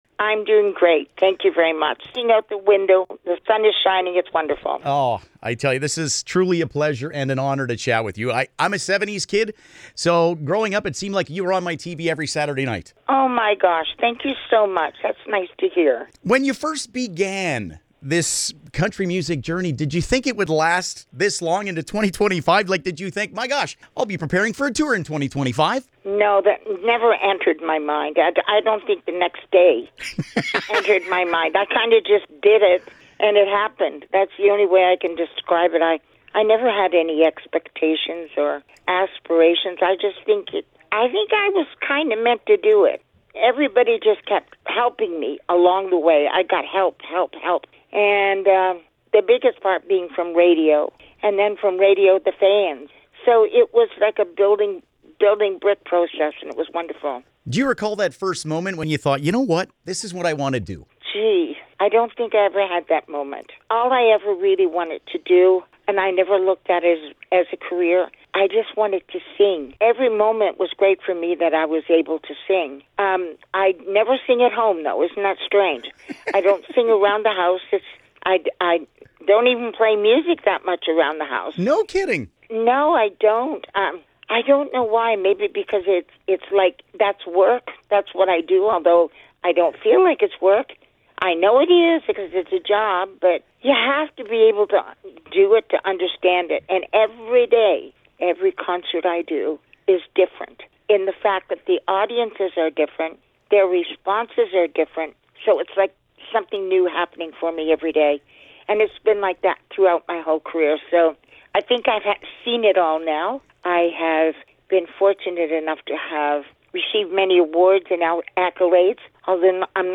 caroll-baker-int.mp3